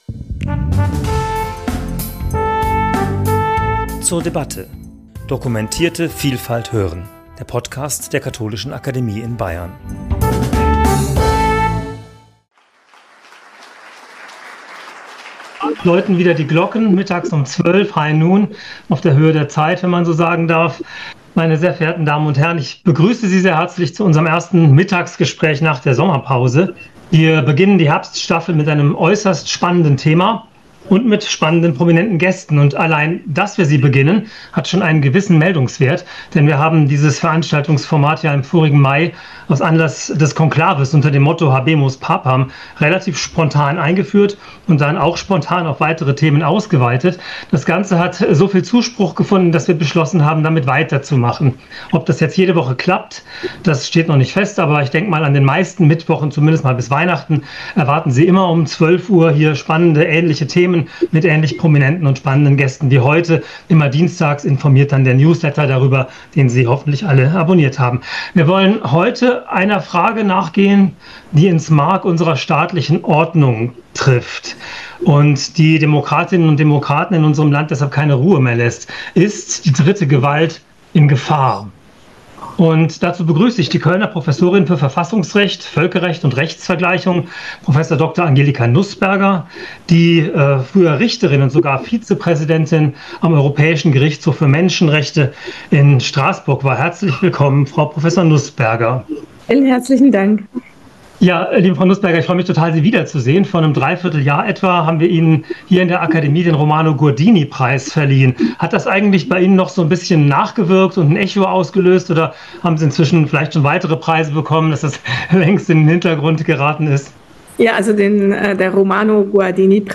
Gespräch zum Thema 'Die Dritte Gewalt in Gefahr' ~ zur debatte Podcast